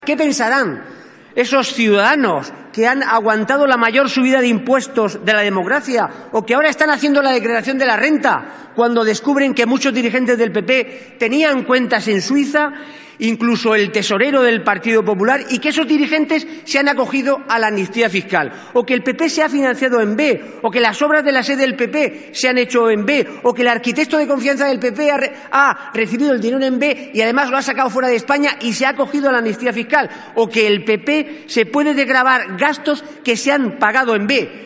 Fragmento de la intervención de Pedro Saura en el pleno del 10/04/2014 defendiendo una Proposición no de ley para combatir el fraude fiscal